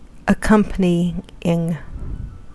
Ääntäminen
Synonyymit accessory concomitant Ääntäminen US UK : IPA : /əˈkʌm.p(ə.)ni.ɪŋ/ Haettu sana löytyi näillä lähdekielillä: englanti Accompanying on sanan accompany partisiipin preesens.